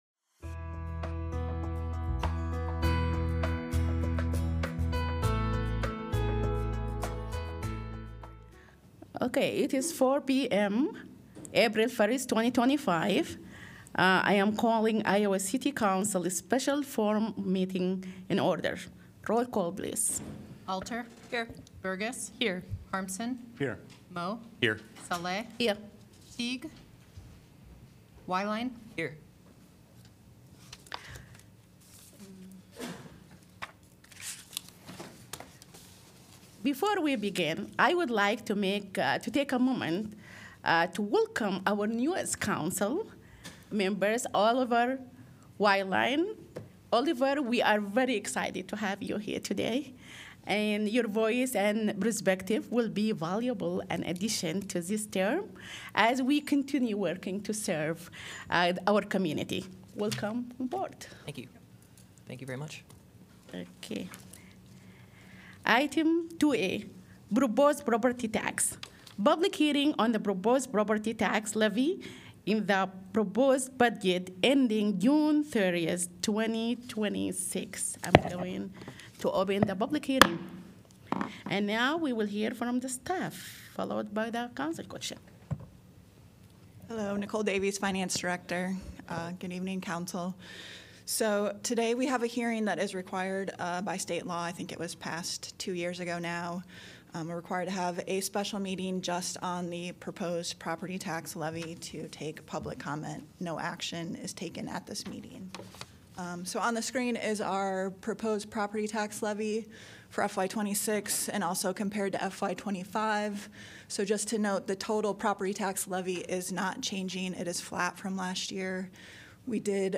Coverage of an Iowa City City Council special formal meeting held on April 1, 2025 to conduct a public hearing.